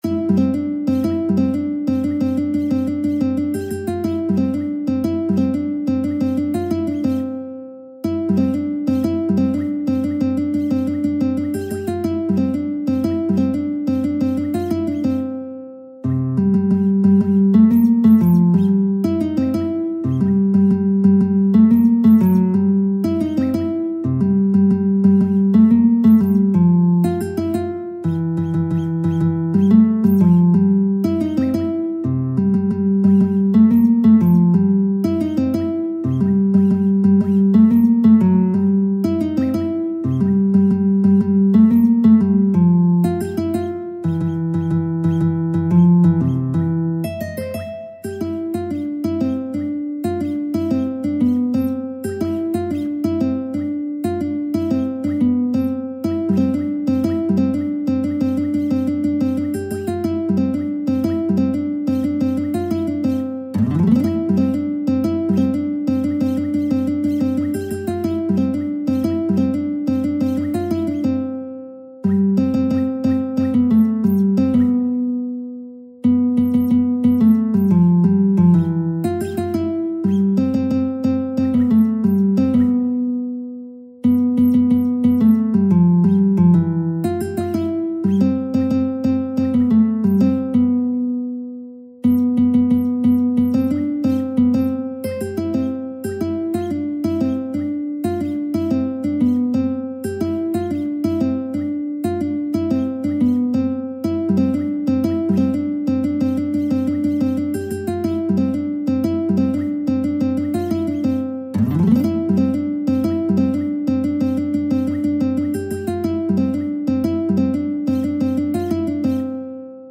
به همراه تبلچر آکورد و ملودی